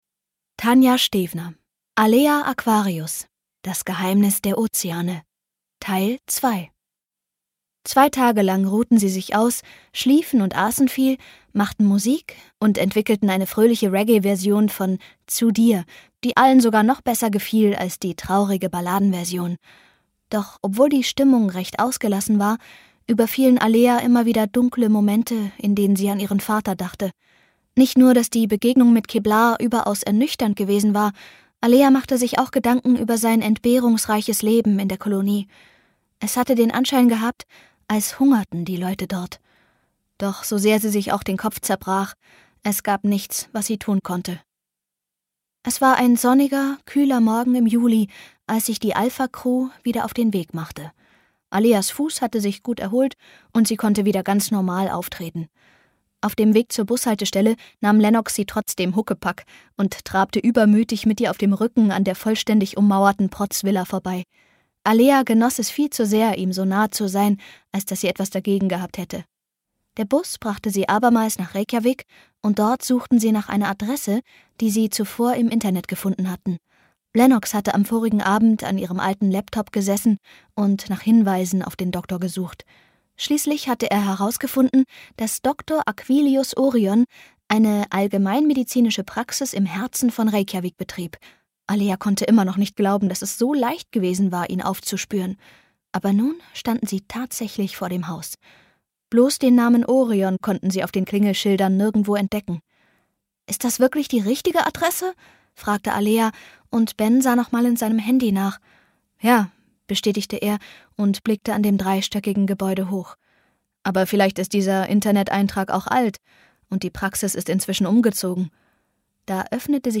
Hörbuch: Alea Aquarius 3 Teil 2.